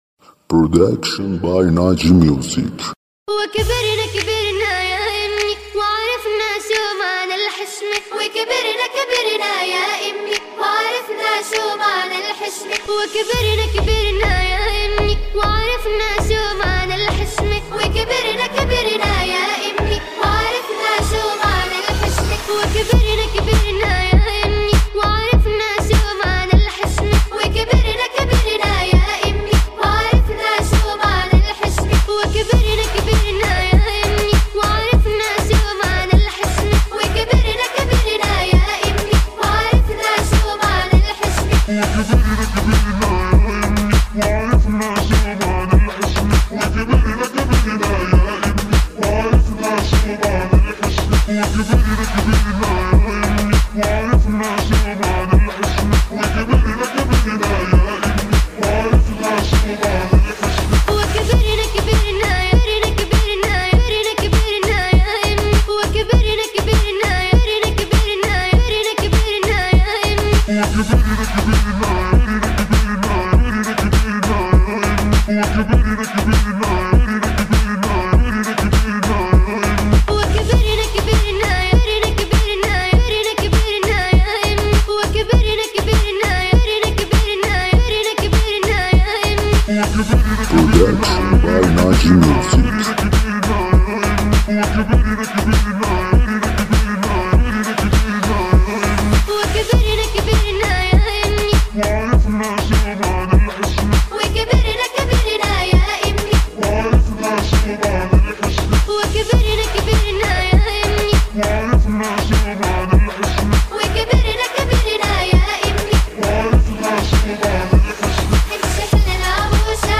دانلود ریمیکس عربی مخصوص رقص